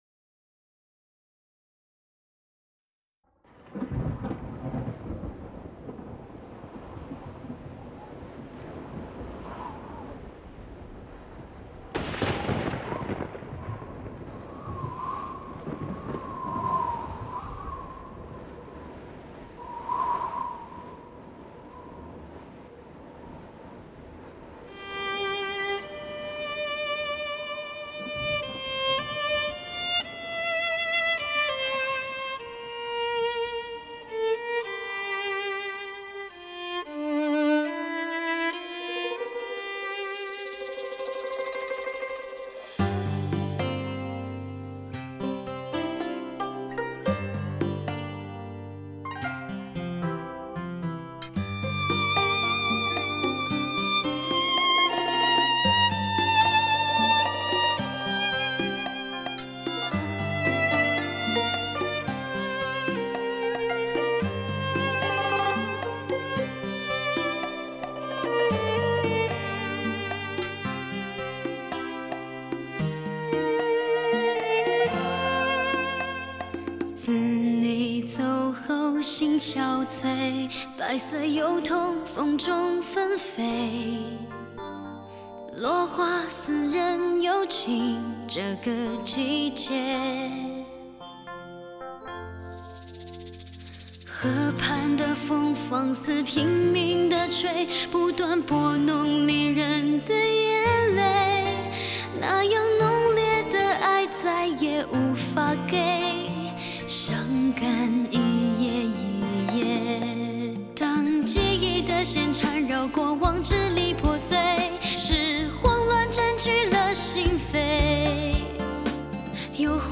性感的磁声泌入您的心扉，犹如清风迎面而来。
世界最新DTS Discrete技术，
真正意义的多声道，100%现场显示目录音再现。